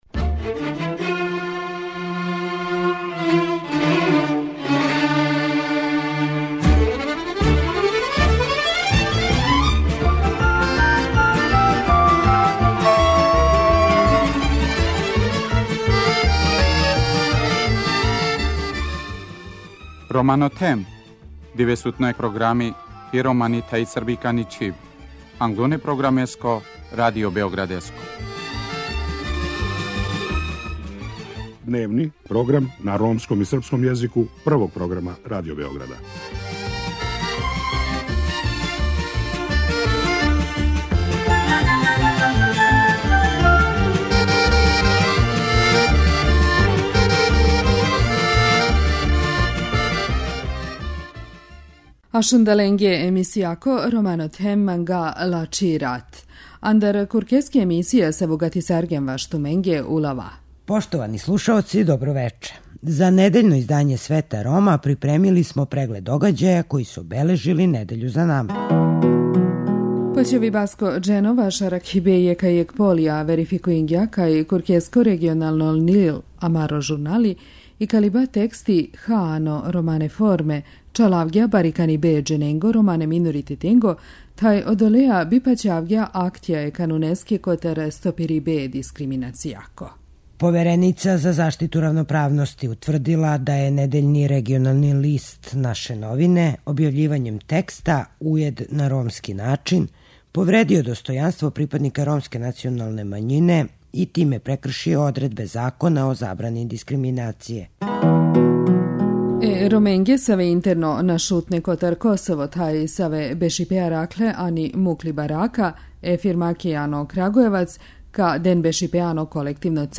Радио Београд 1, 19.20